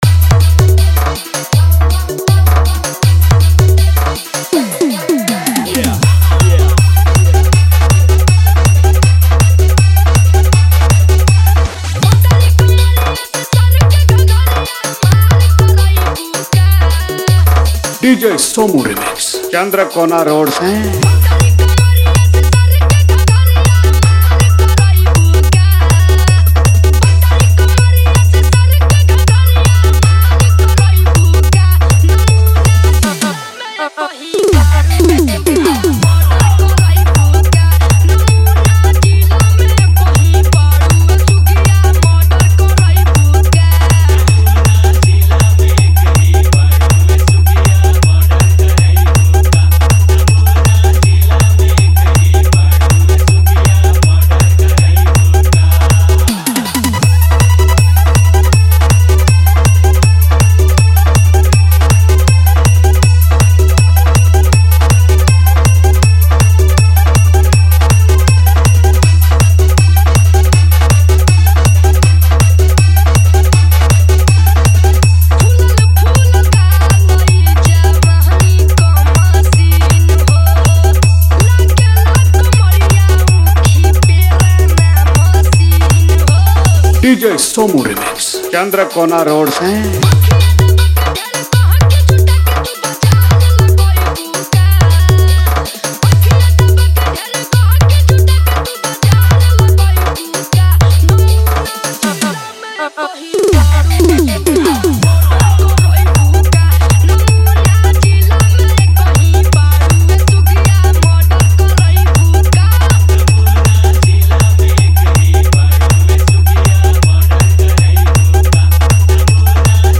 Competition Mix